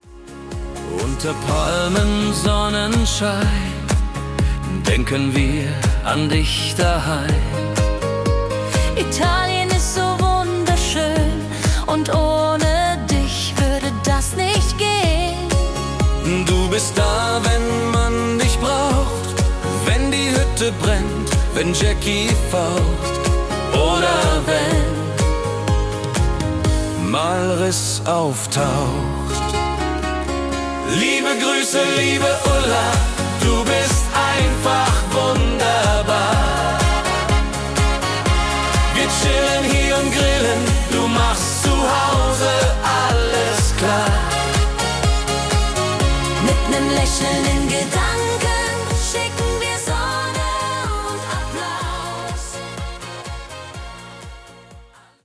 Volkstümlich